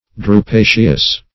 Search Result for " drupaceous" : Wordnet 3.0 ADJECTIVE (1) 1. of or related to a drupe ; The Collaborative International Dictionary of English v.0.48: Drupaceous \Dru*pa"ceous\, a. [Cf. F. drupac['e].]